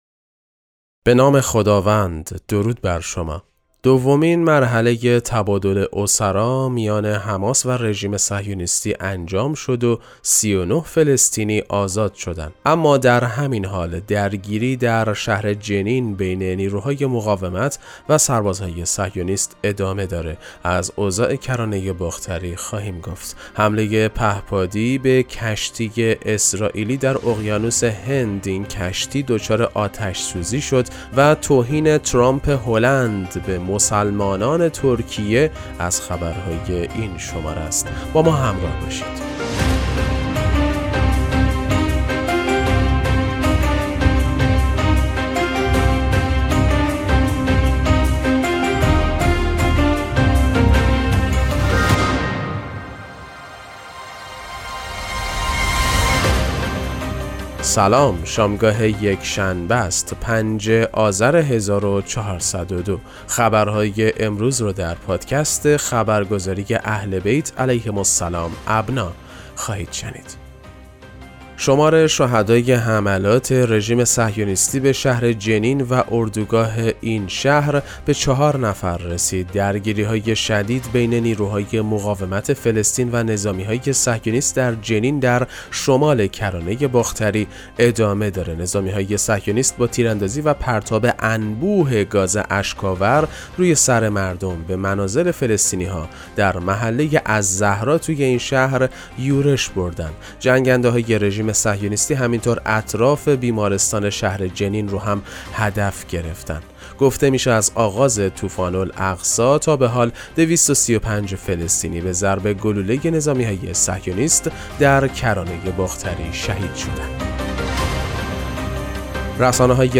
پادکست مهم‌ترین اخبار ابنا فارسی ــ 5 آذر 1402